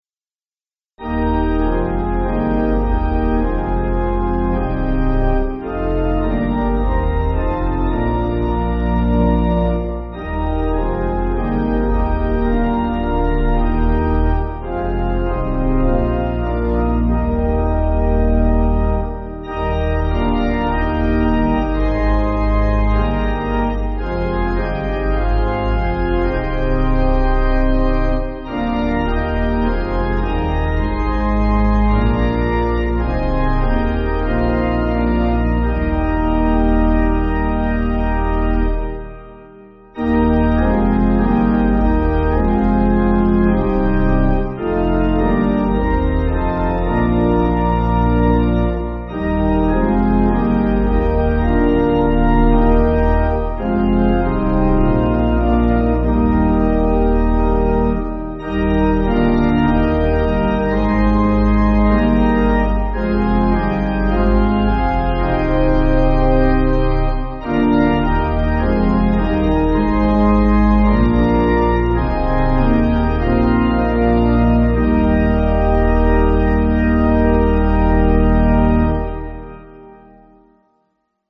Organ
(CM)   2/Eb